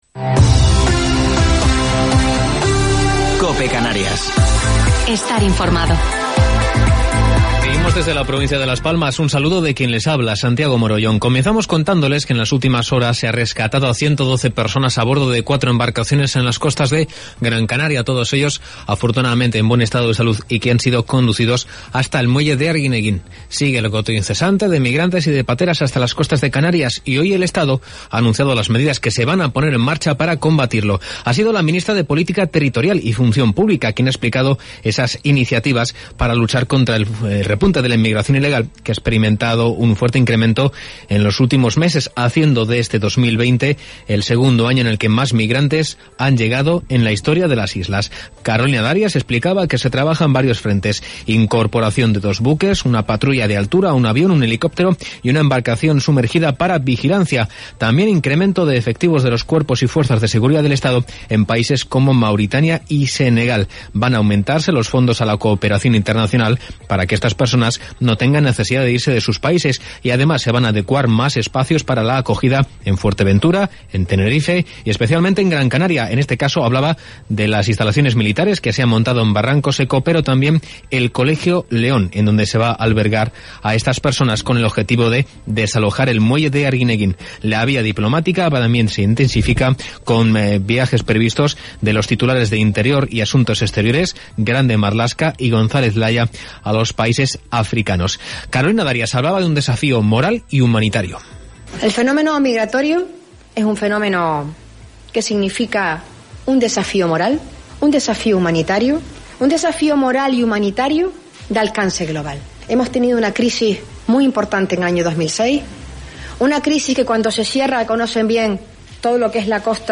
Informativo local 13 de Noviembre del 2020